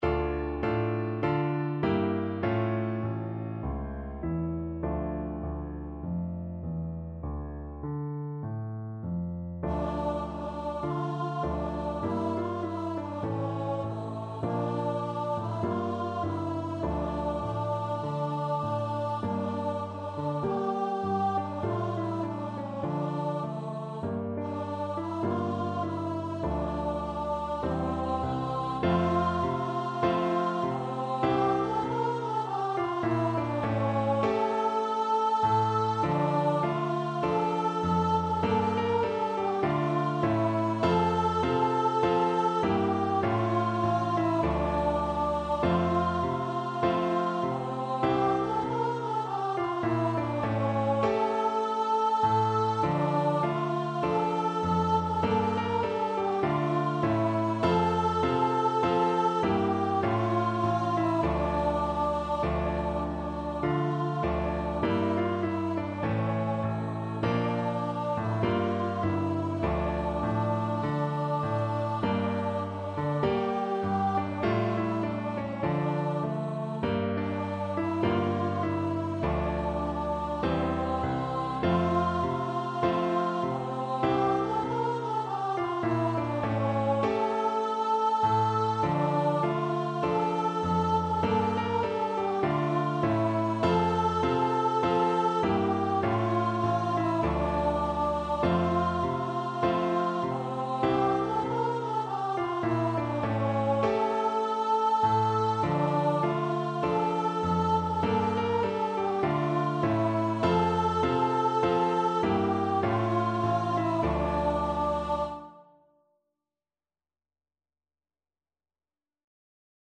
4/4 (View more 4/4 Music)
Voice  (View more Easy Voice Music)
Traditional (View more Traditional Voice Music)